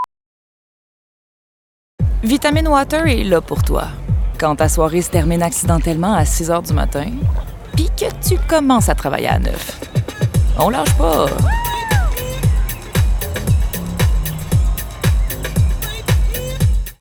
Yeux bruns                  Langue(s) français, anglais (avec accent)
Voix annonceur – Vitamin Water
Confiante, touche d’humour, familière